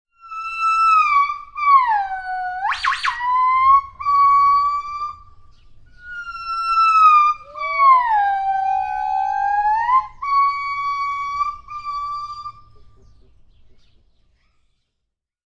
Здесь вы найдете разнообразные крики, песни и коммуникационные сигналы этих обезьян, записанные в естественной среде обитания.
Гиббон издает необычный звук